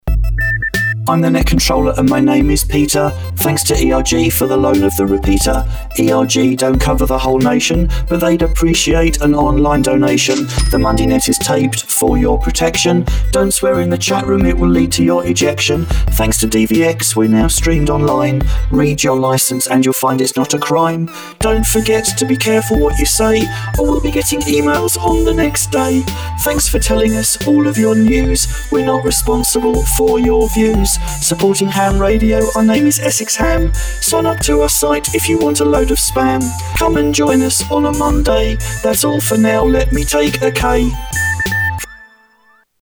See below for our rather tongue-in-cheek Songify version!!
mnn-disclaimer-songify.mp3